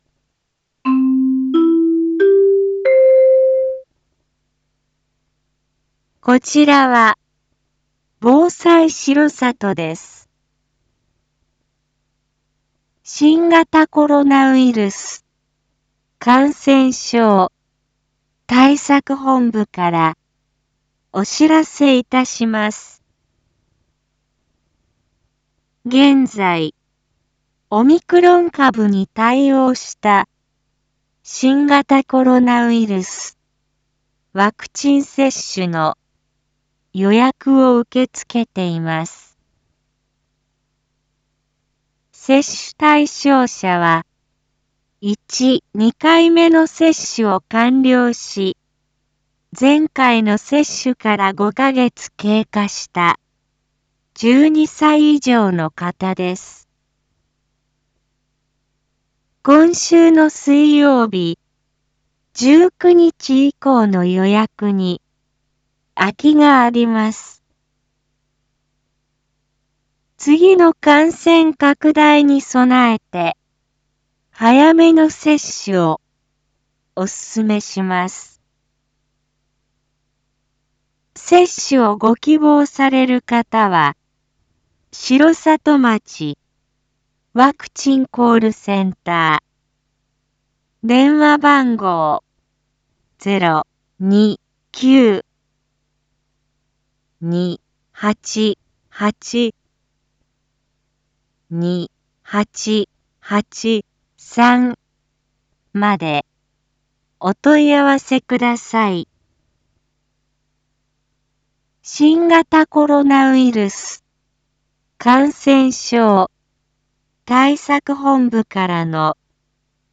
一般放送情報
Back Home 一般放送情報 音声放送 再生 一般放送情報 登録日時：2022-10-17 07:02:20 タイトル：新型コロナウイルスワクチン接種について インフォメーション：こちらは、防災しろさとです。